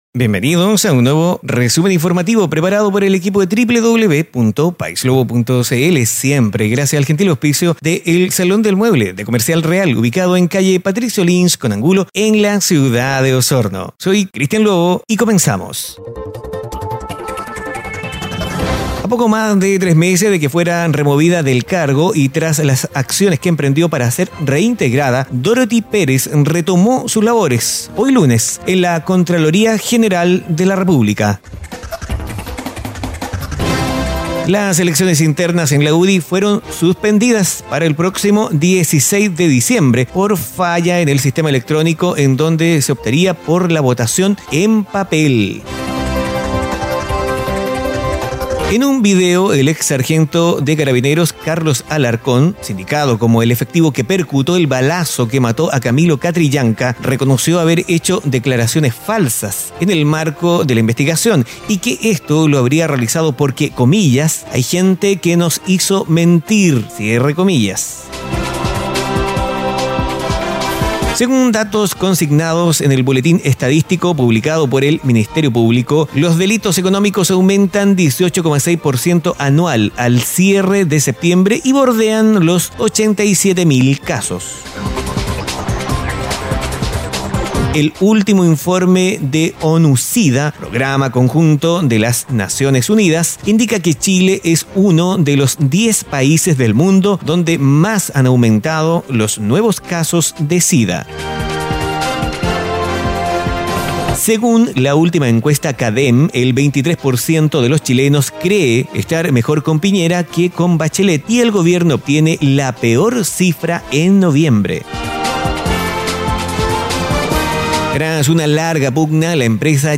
Noticias en pocos minutos.